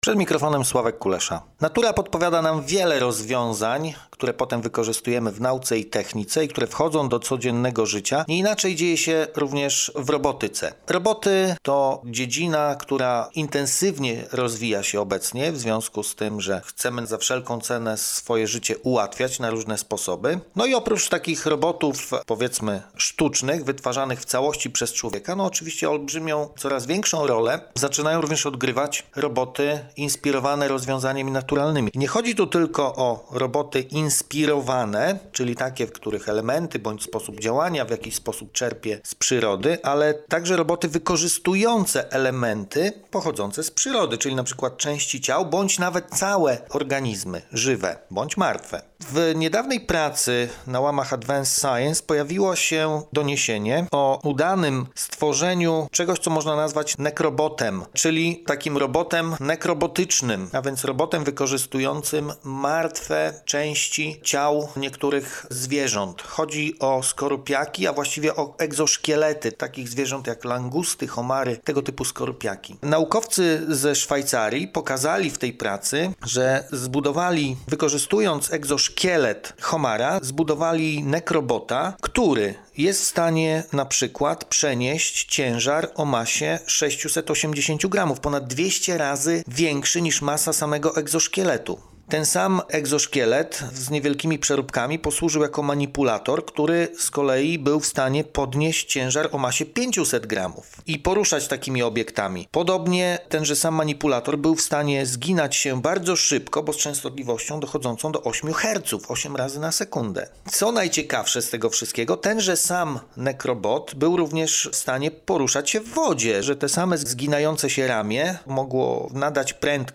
Postój z Nauką robimy sobie na antenie Radia UWM FM od poniedziałku do czwartku około 14:15 w audycji Podwójne Espresso.